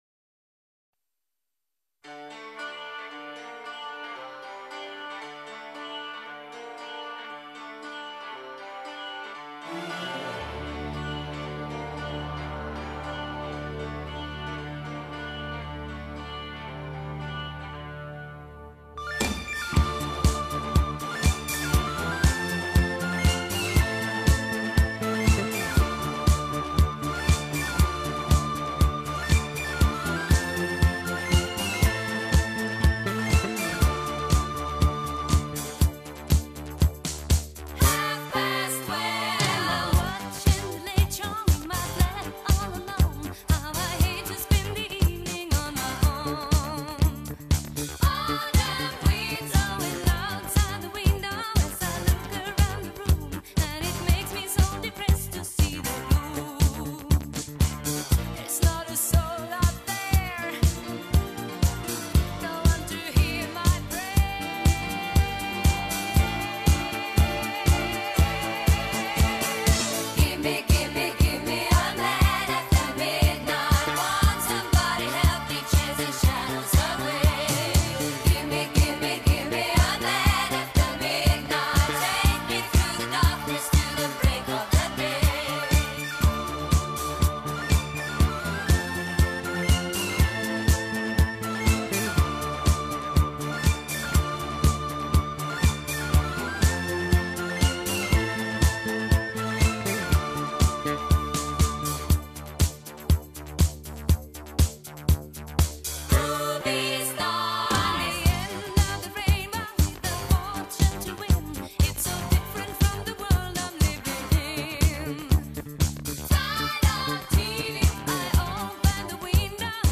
grup pop suedez